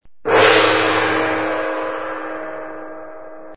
GONG GONG INDIO
Tonos EFECTO DE SONIDO DE AMBIENTE de GONG GONG INDIO
gong_-_GONG_indio.mp3